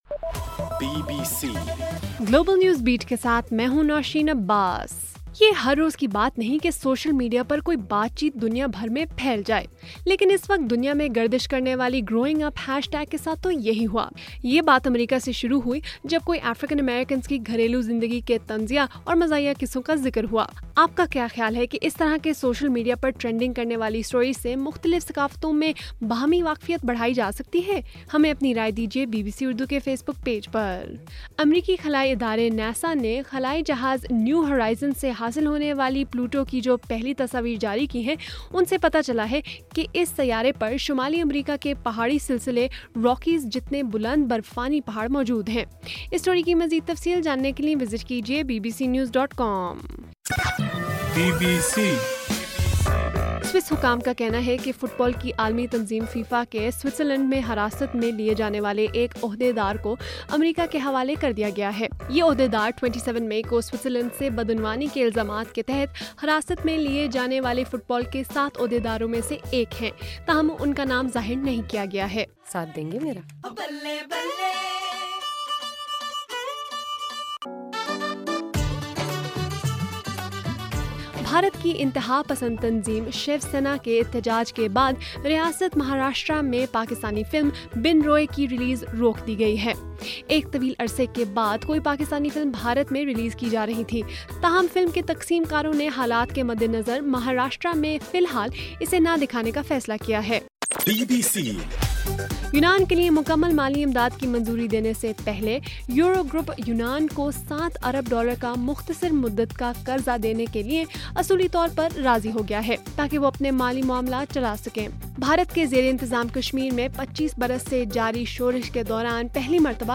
جولائی 16: رات 8 بجے کا گلوبل نیوز بیٹ بُلیٹن